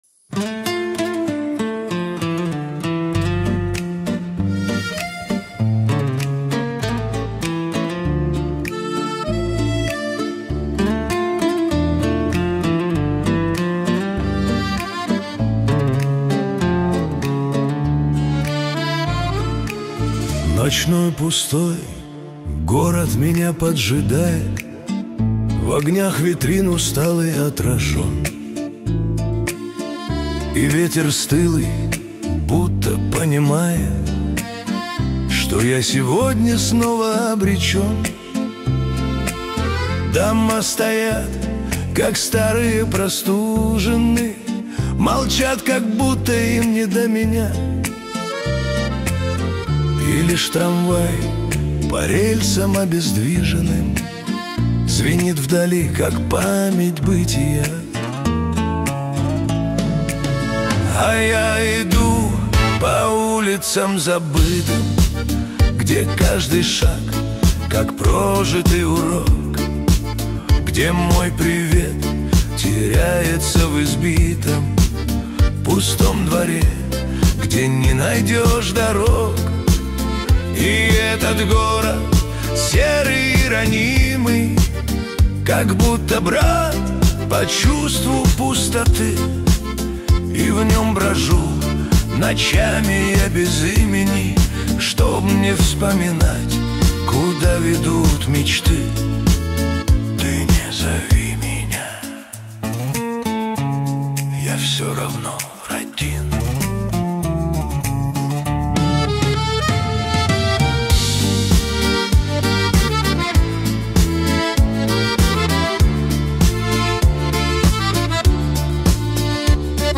13 декабрь 2025 Русская AI музыка 84 прослушиваний